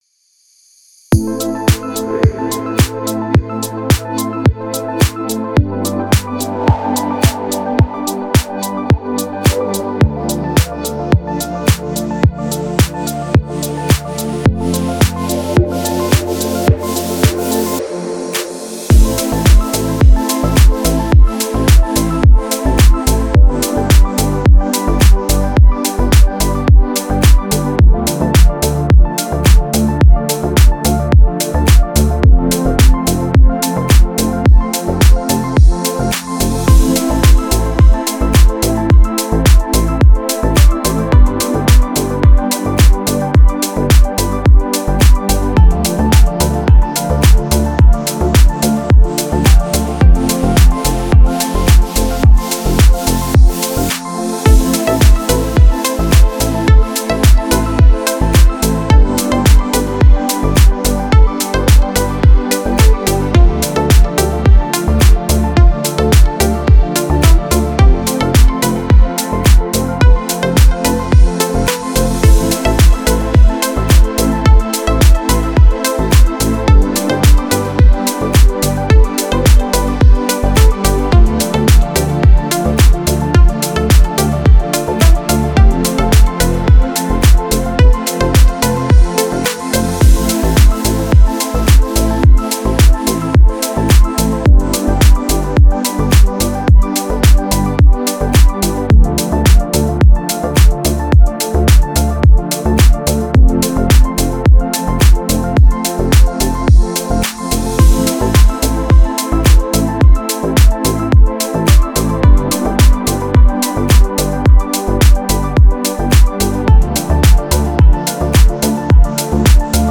Deep House музыка